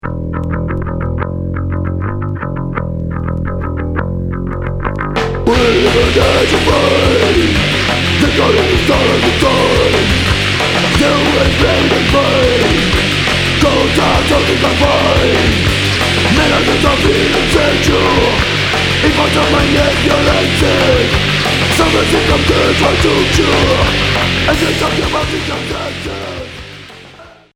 Street punk core